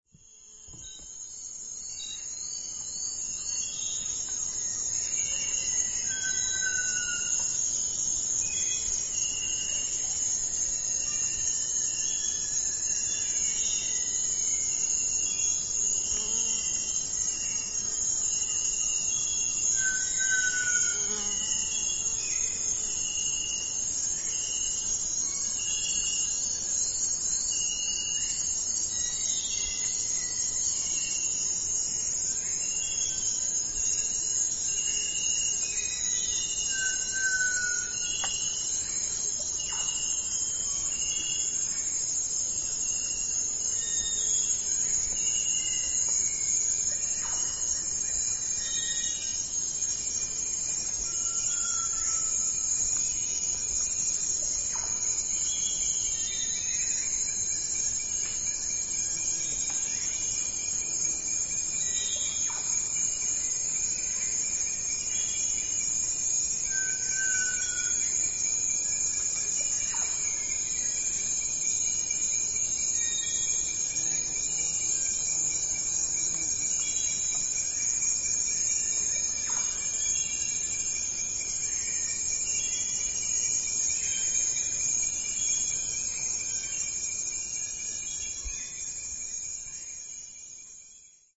Here you hear the two-note call with the second note down-slurred. It’s joined by a second bird for a duet.
Seen at dusk, ACTS field station, Peru.
ochrestripedantpitta.mp3